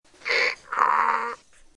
Sound Effect from Pixabay
“Donkey Bray” – Pixabay Content License
donkey-bray-36757.mp3